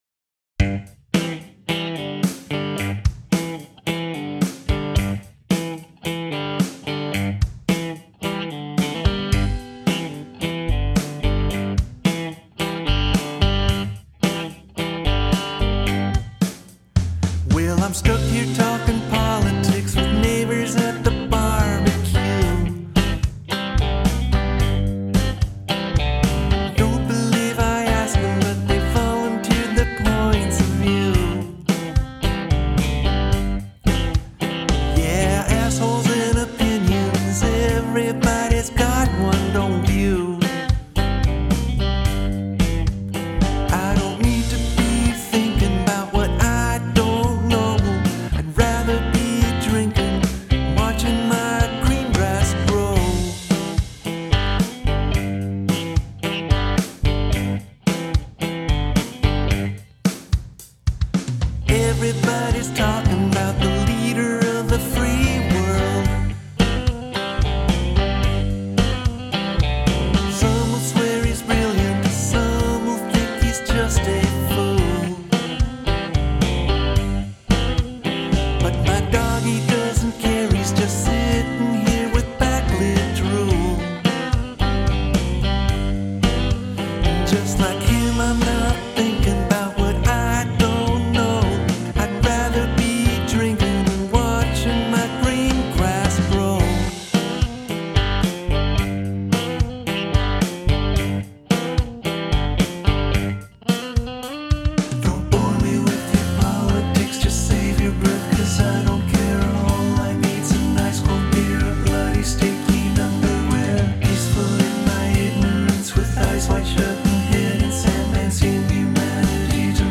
With this one I was going for a Rolling Stones feel, with Keith Richards open G tuning on the electric guitar.